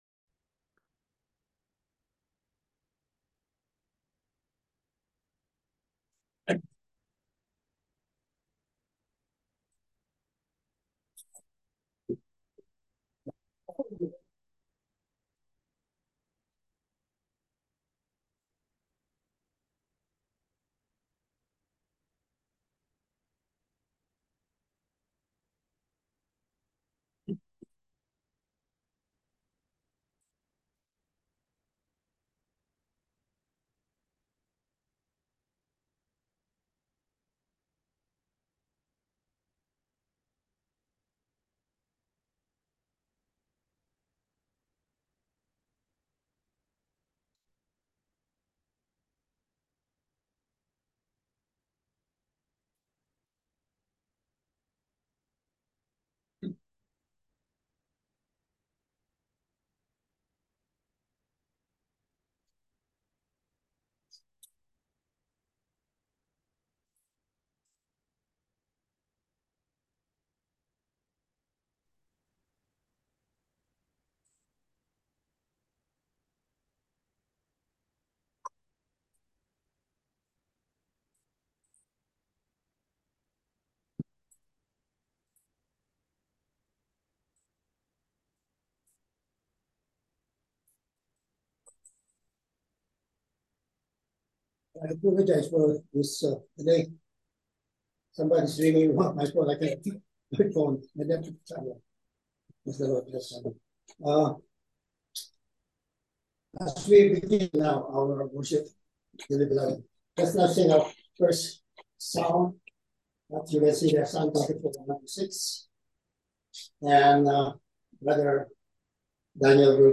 Passage: John 17:1-10 Service Type: Sunday Evening Service The Lord’s High Priestly Prayer « Sunday Morning